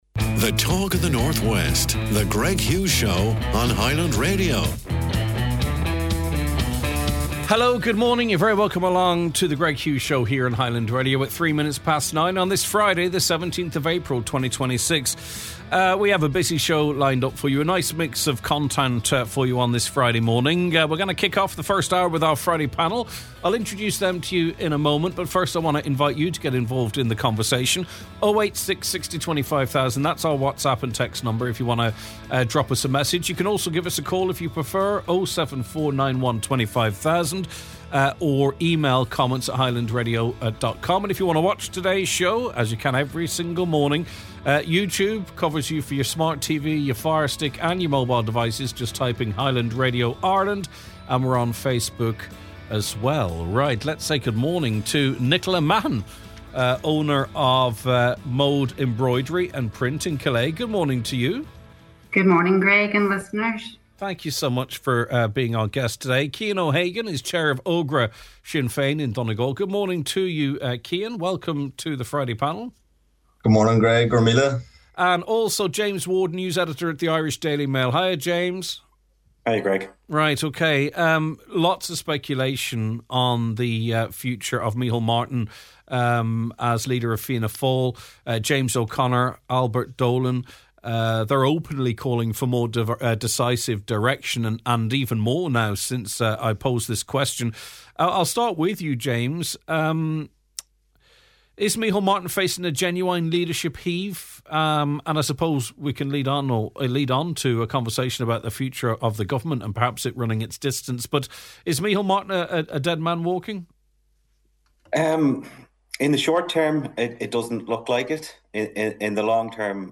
It’s the Friday Panel!